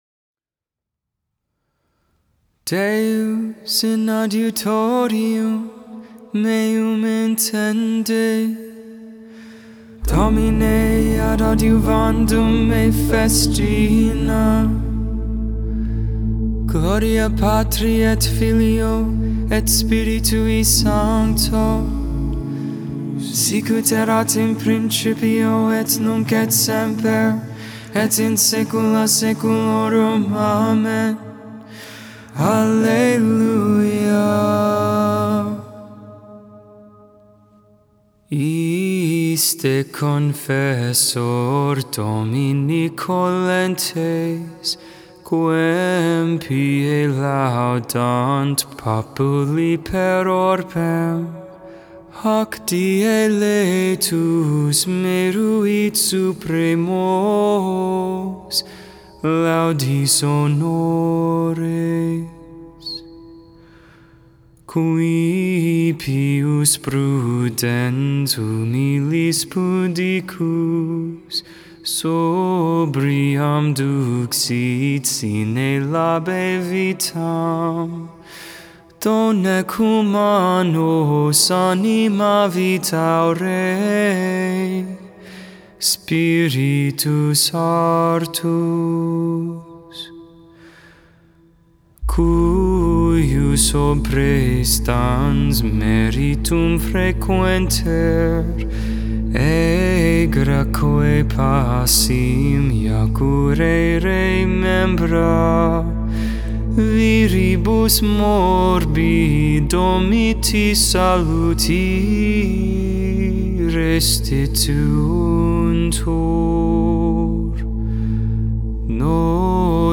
Anonymous 8th Century Hymn